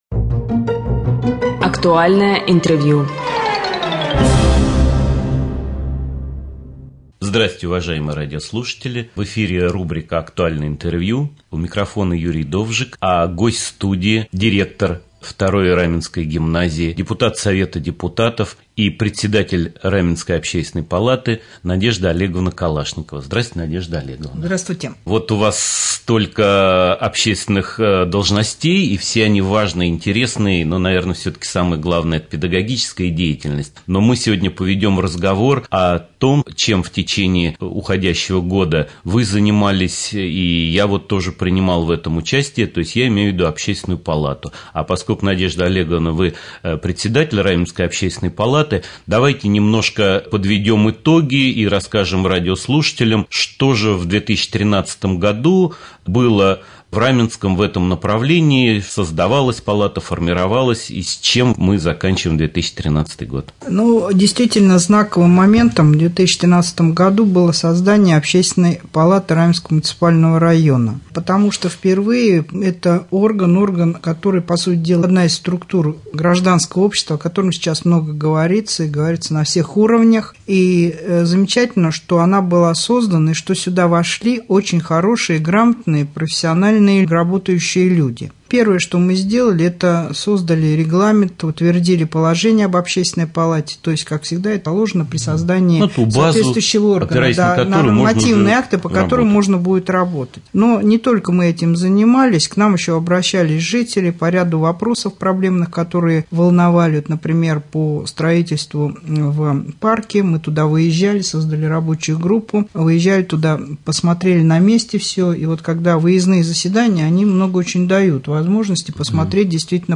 В студии Надежда Олеговна Калашникова
Гость студии директор второй гимназии, депутат совета депутатов, председатель общественной палаты Н.О.Калашникова.